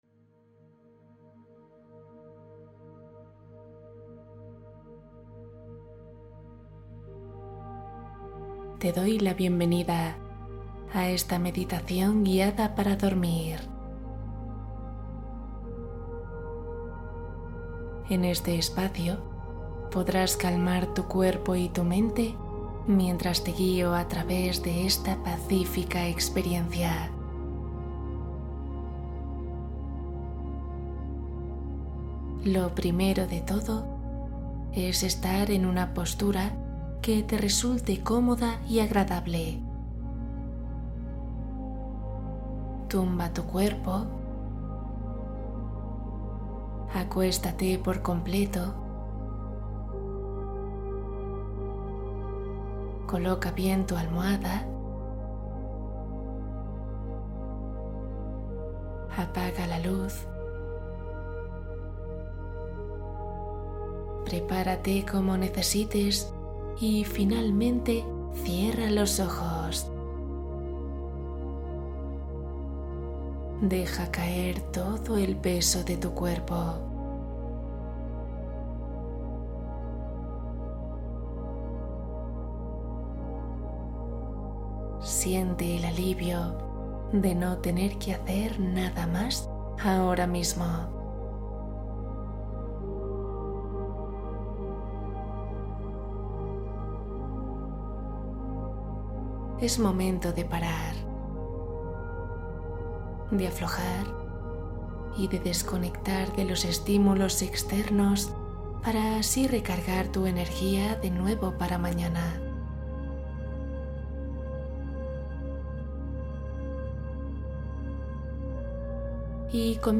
Duerme profundo ❤ Meditación para sanar el corazón y soltar